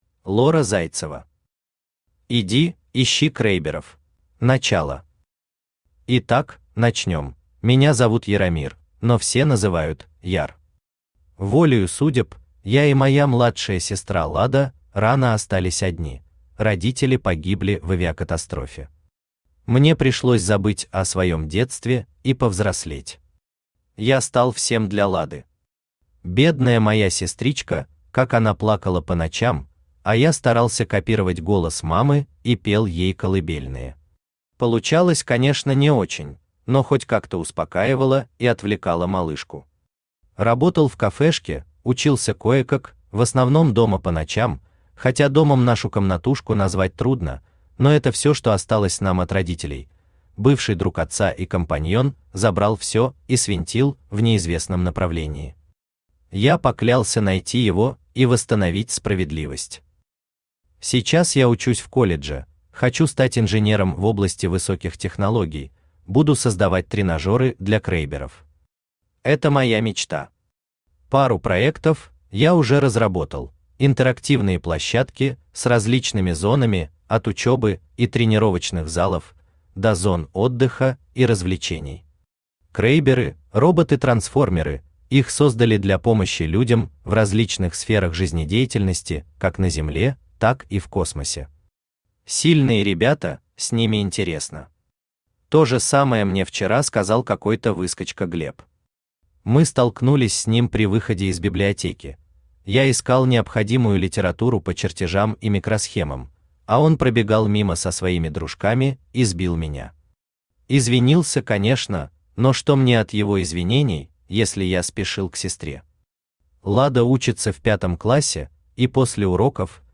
Аудиокнига Иди, ищи крэйберов!